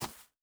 Shoe Step Grass Medium C.wav